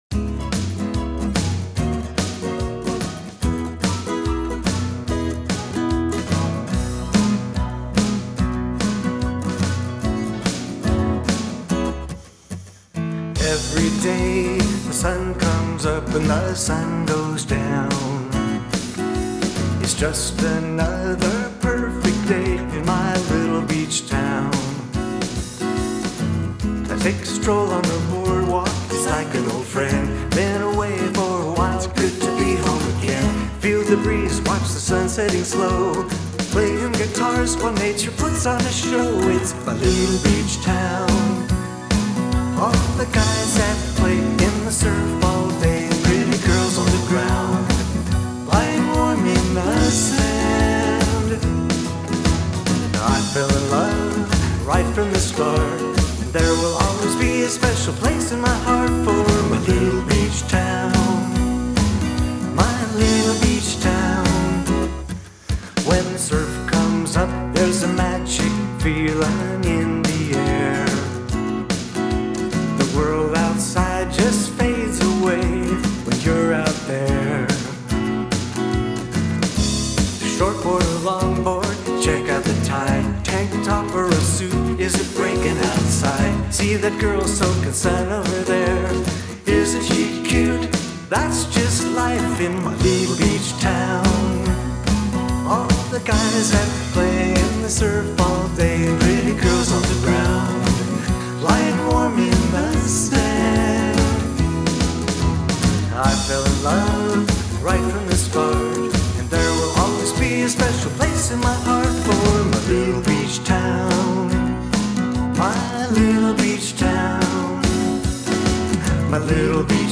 rhythm guitar and lead vocal in my studio, and he supplied a drum and piano backing track that he created at his home. And then I had some fun mixing and mastering the project after adding lead guitar and vocal harmonies.